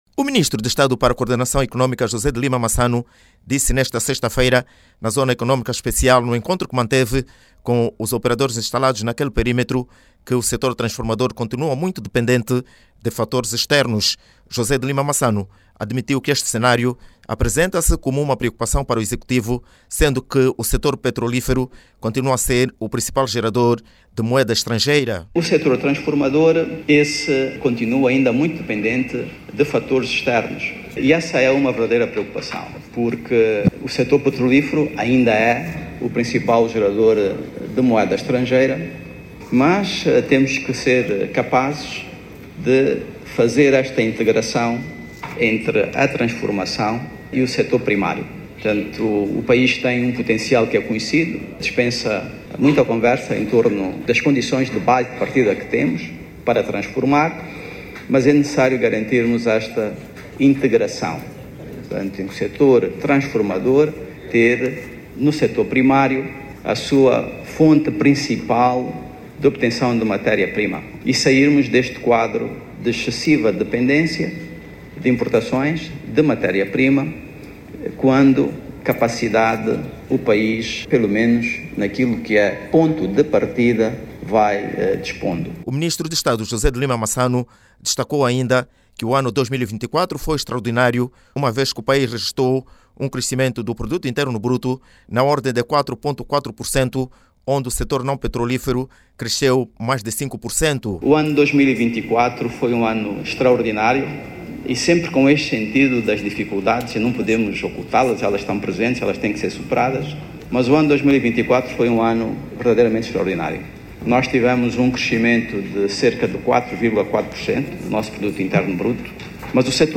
José de Lima Massano fez esta declaração durante a visita que realizou, nesta sexta-feira, à Zona Económica Especial Luanda-Bengo, no âmbito do reforço do diálogo com as unidades fabris aí instaladas.